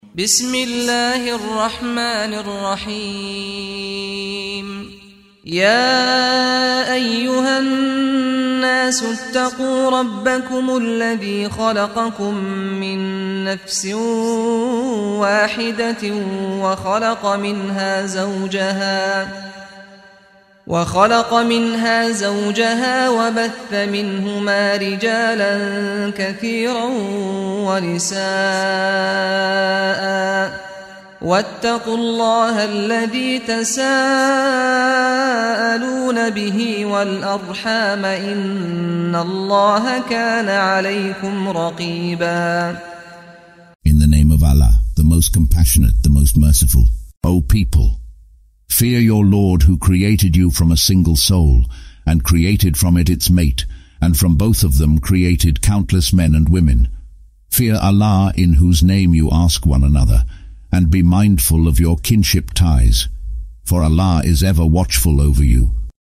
Audio version of Surah An-Nisa ( The Women ) in English, split into verses, preceded by the recitation of the reciter: Saad Al-Ghamdi.